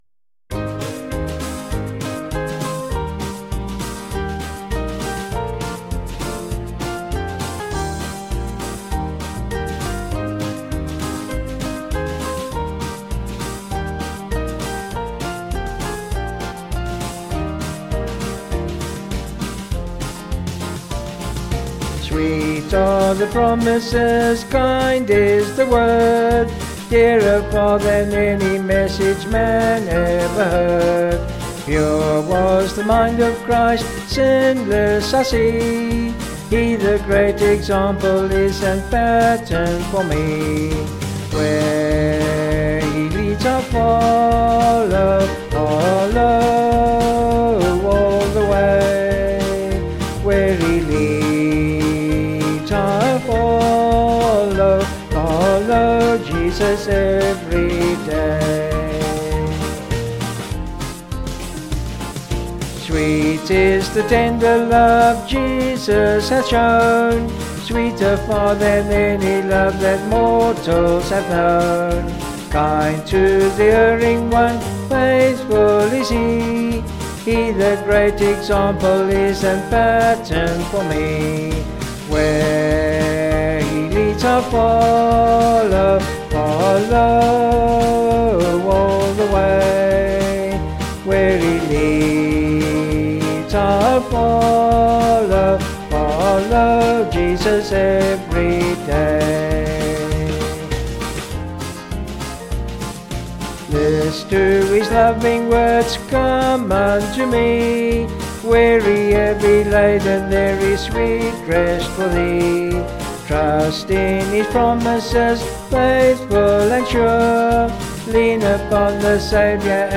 Vocals and Band
Sung Lyrics